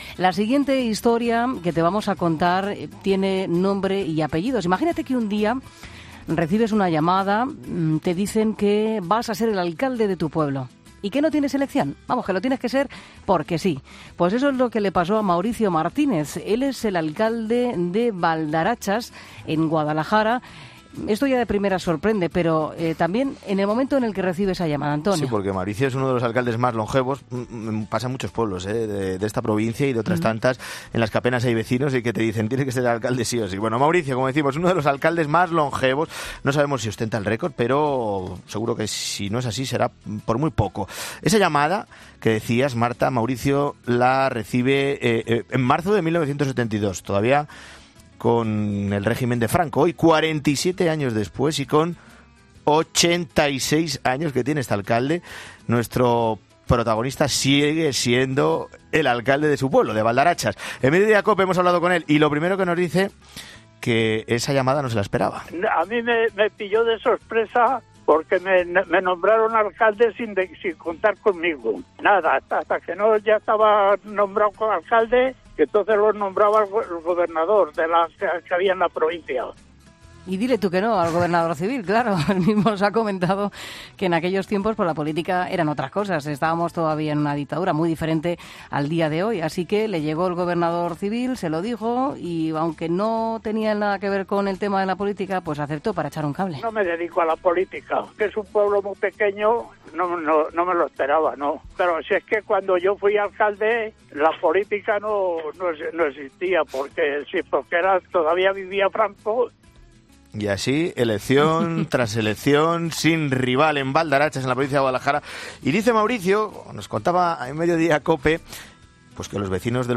En 'Mediodía COPE' le hemos pedido que haga balance de todos estos años al frente del pueblo: "Claro que ha ido a mejor, por eso me siguen votando, antes si caía uno enfermo teníamos que desplazarnos a otro pueblo, ahora viene el enfermero, ha cambiado mucho".